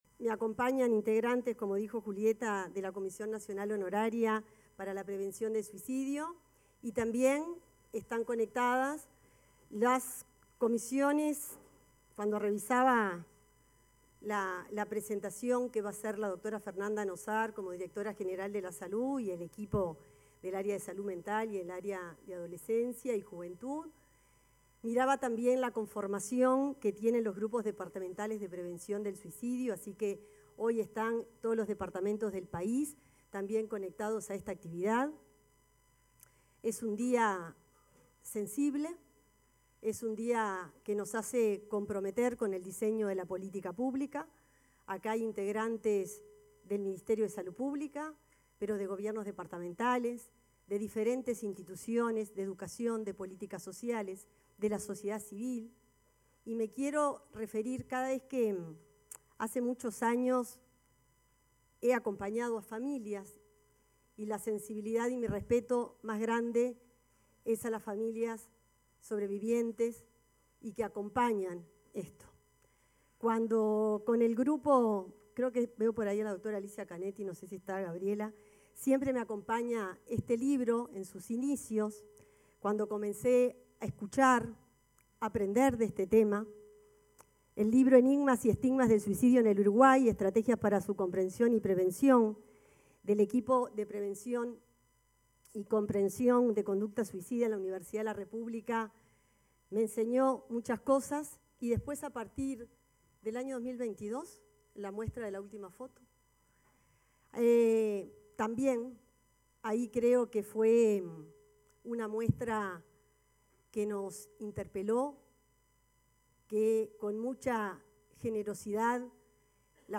Palabras de la ministra de Salud Pública, Cristina Lustemberg